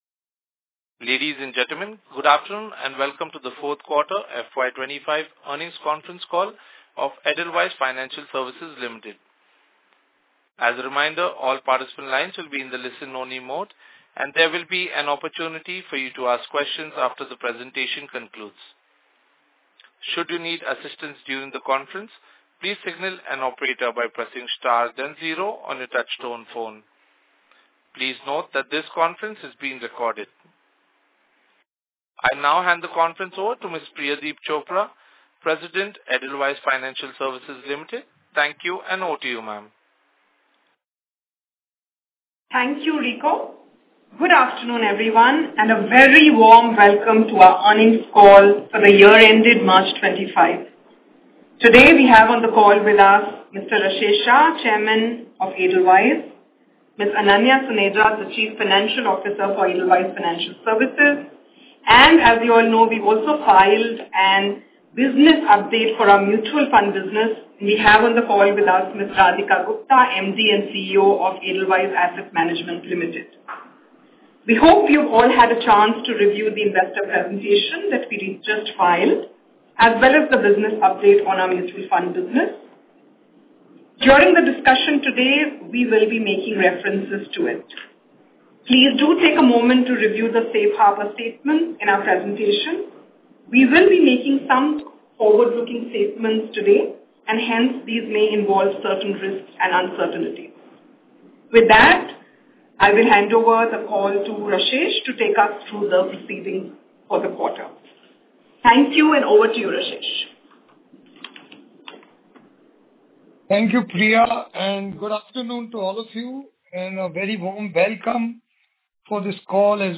Earnings-Call-Audio-Q4FY25.mp3